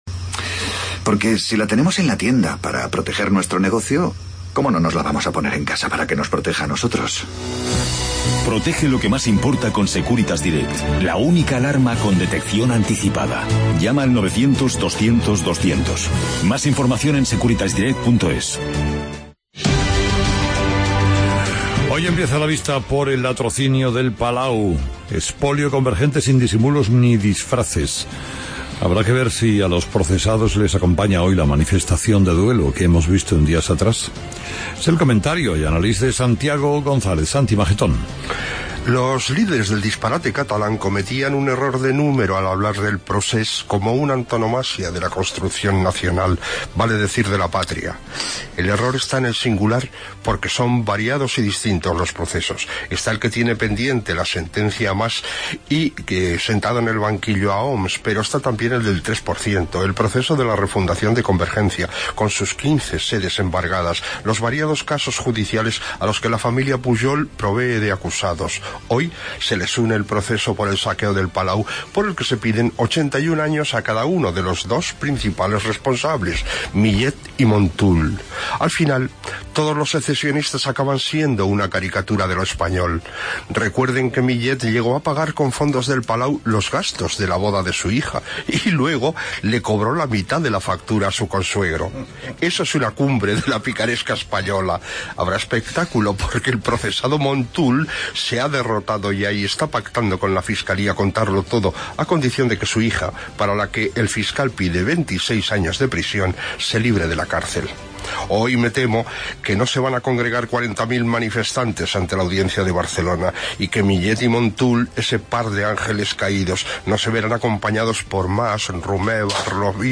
Informativo 1 de Marzo